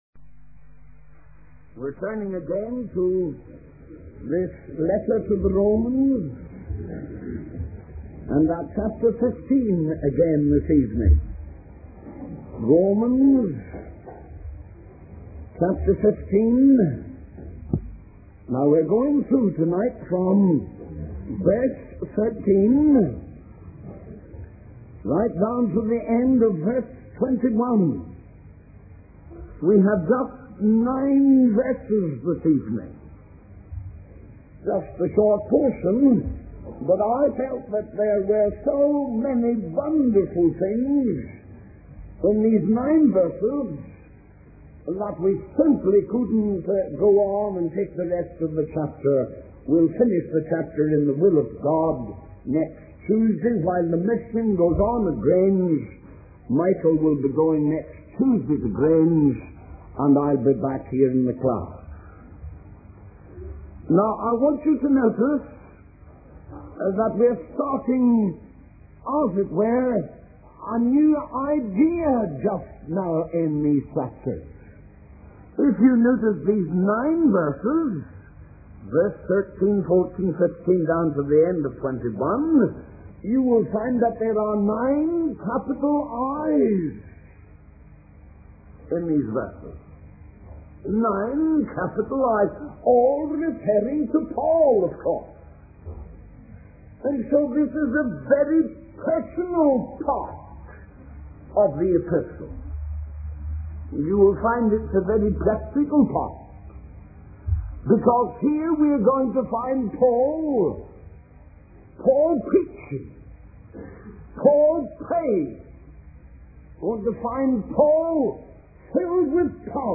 In this sermon, the speaker emphasizes the importance of preaching the word of God to the Gentiles.